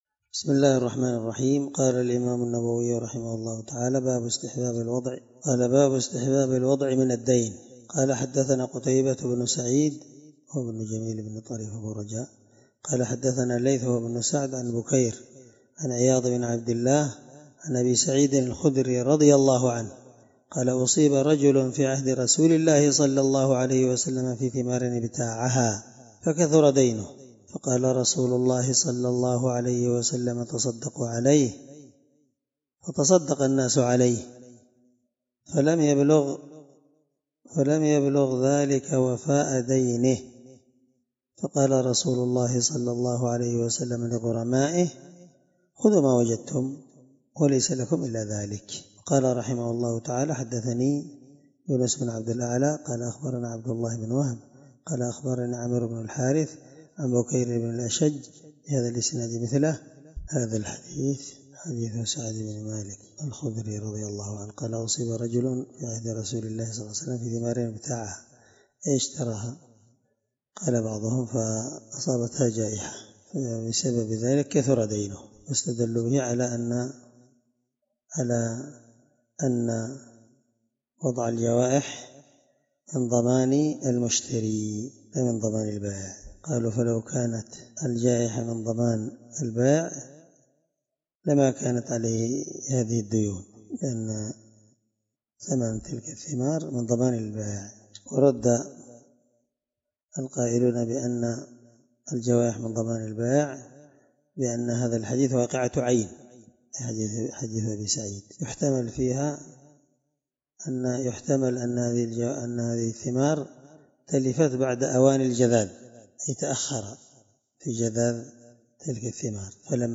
الدرس4من شرح كتاب المساقاة حديث رقم(1556) من صحيح مسلم